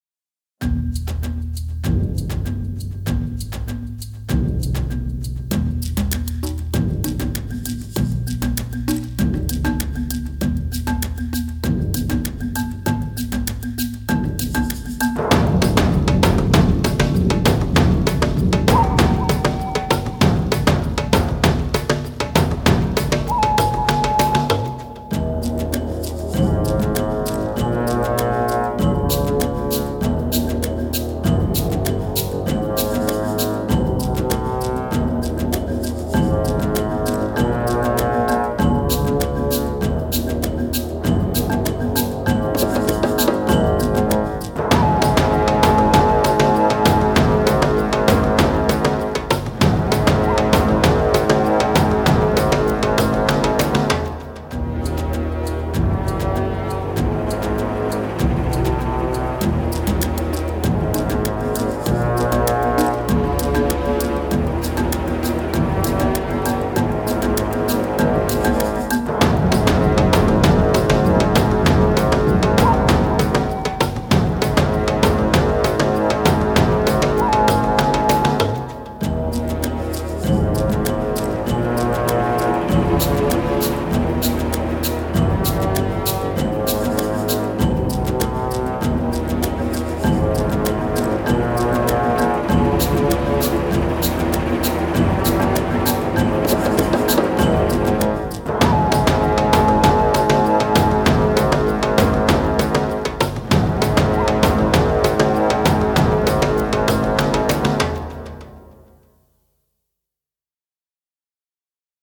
Adventure track for platform worlds and boss battles.